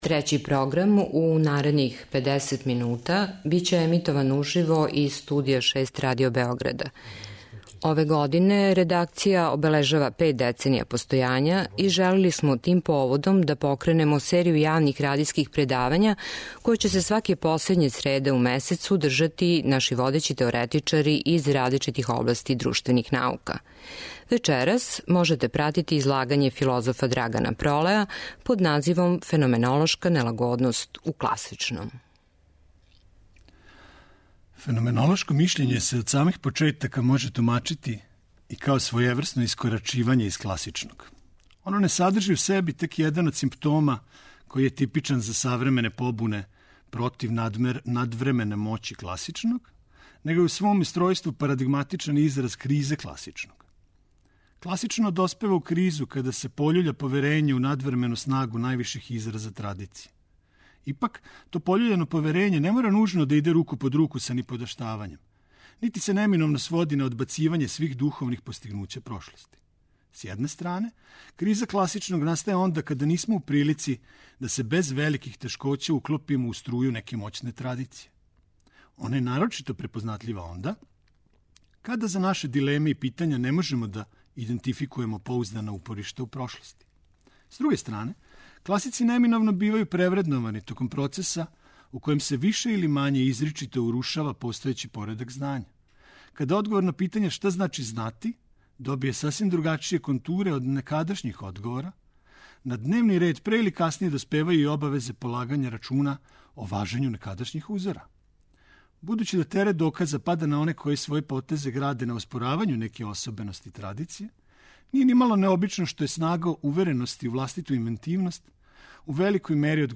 Радијско предавање Трећег програма
У години у којој Трећи програм обележава пет деценија постојања, наша редакција организоваће серију предавања која ће се једном месечно одржавати у Студију 6.